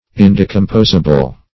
Search Result for " indecomposable" : The Collaborative International Dictionary of English v.0.48: Indecomposable \In*de`com*pos"a*ble\, a. [Pref. in- not + decomposable: cf. F. ind['e]composable.]